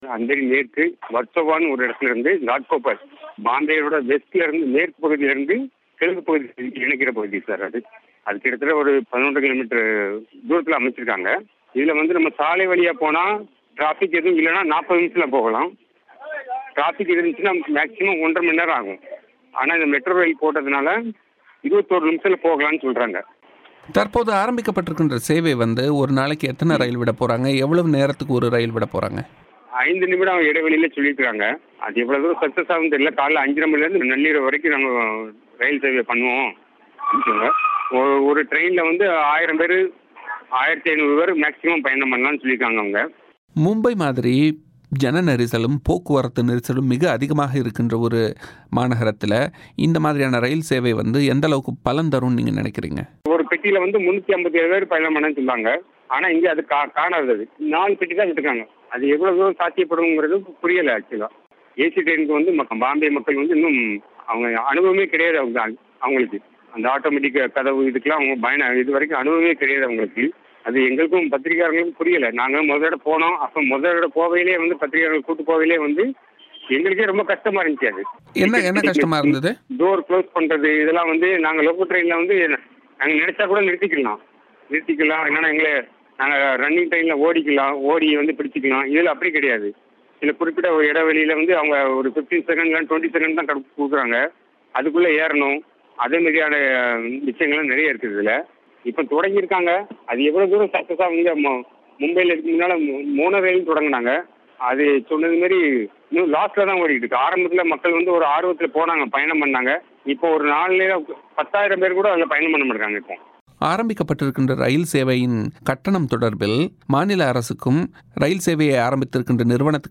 தமிழோசைக்கு வழங்கிய செவ்வி